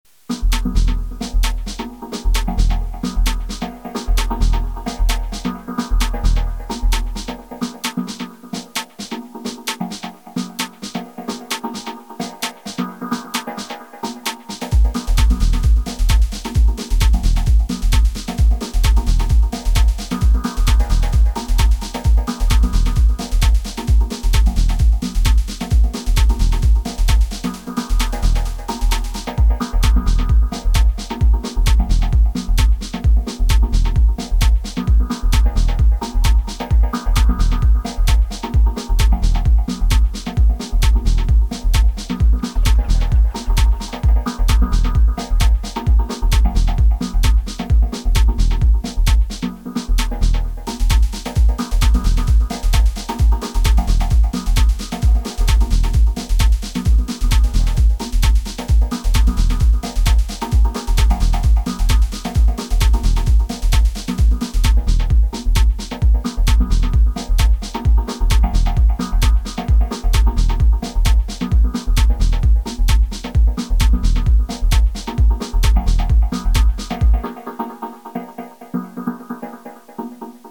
playing around resampling some sounds from Ableton into the DTII